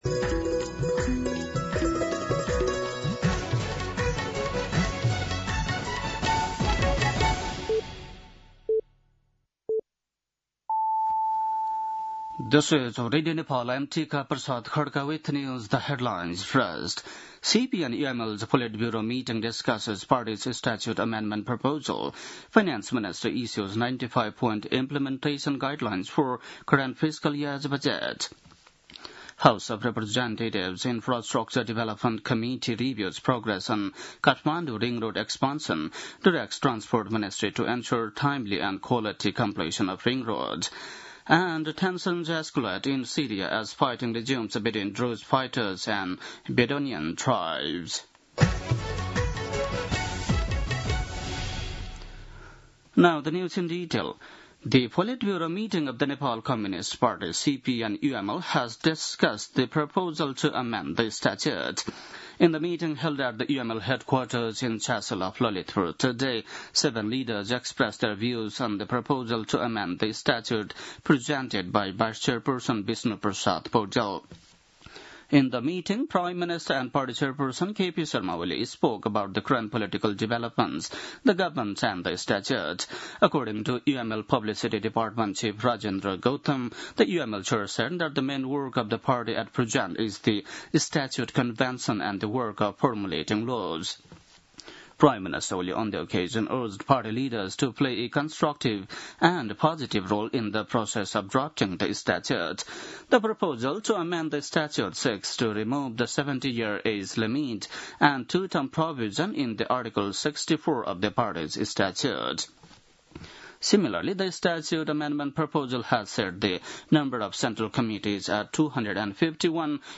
बेलुकी ८ बजेको अङ्ग्रेजी समाचार : २ साउन , २०८२